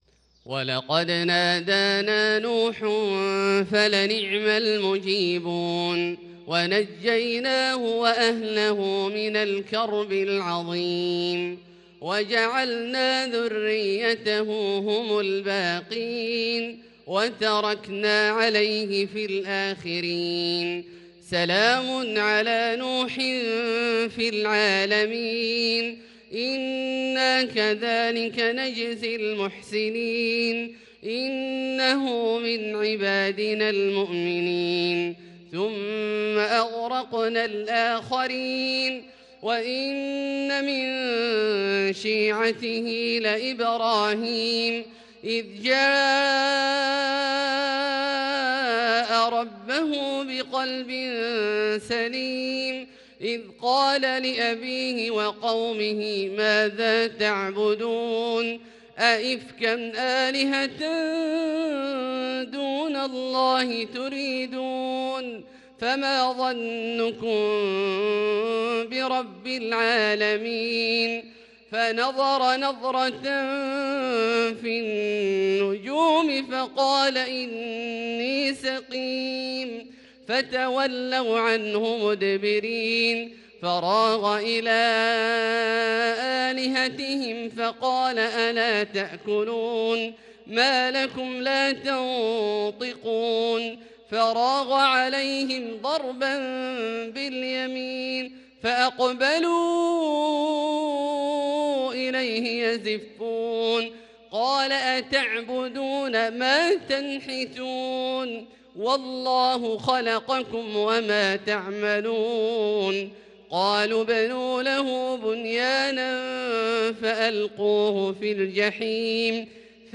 صلاة الفجر 8-4-1442هـ | سورة الصافات ( 75-182 ) > ١٤٤٢ هـ > الفروض - تلاوات عبدالله الجهني